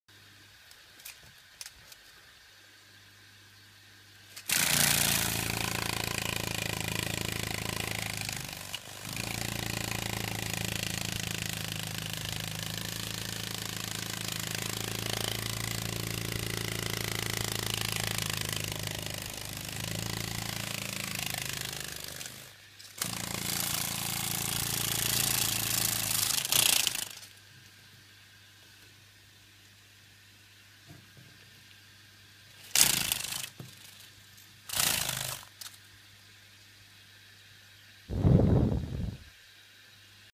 На этой странице собраны разнообразные звуки майского жука: от характерного жужжания до шума крыльев в полете.
Звук взлетающего майского жука и взмах его крыльев